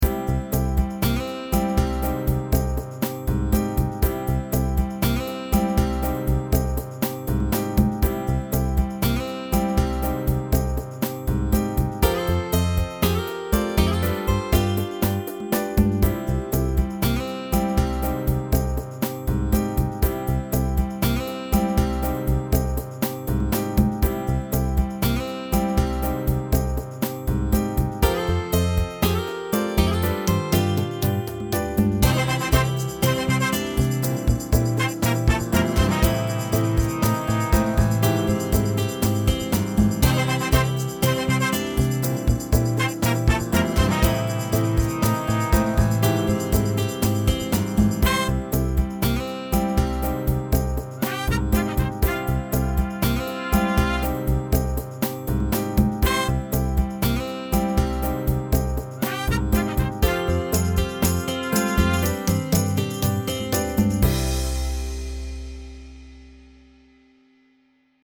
A simple song produced in Apple’s Garage Band (What d’ya know, a happy, peppy tune for a change!)